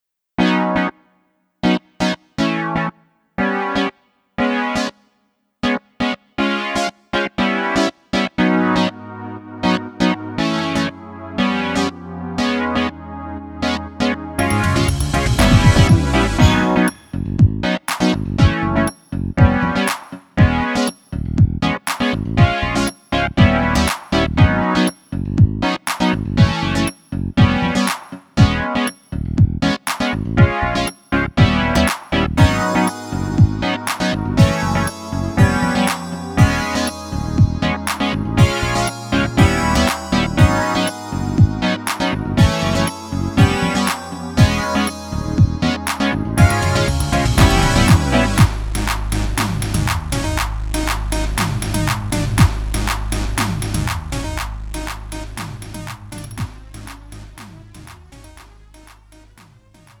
음정 -1키 3:35
장르 가요 구분 Lite MR
Lite MR은 저렴한 가격에 간단한 연습이나 취미용으로 활용할 수 있는 가벼운 반주입니다.